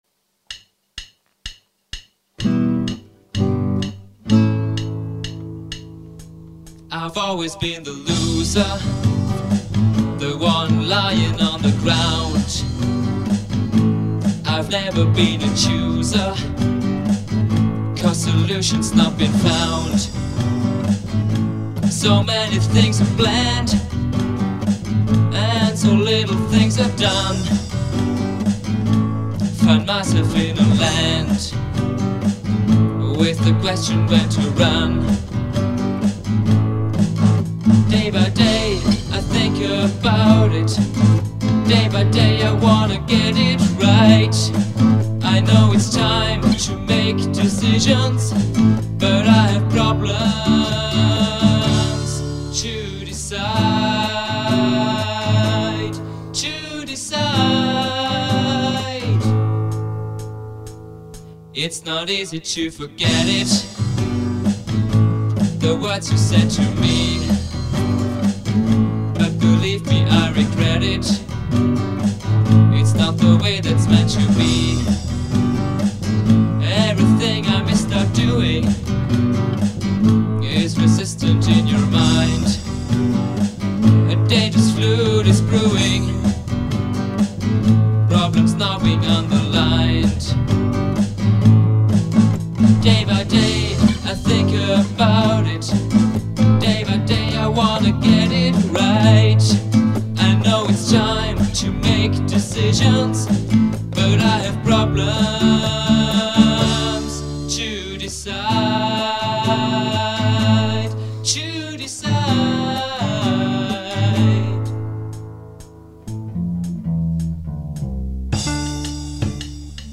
die Studioband (1995-1997)